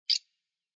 PixelPerfectionCE/assets/minecraft/sounds/mob/rabbit/idle2.ogg at f70e430651e6047ee744ca67b8d410f1357b5dba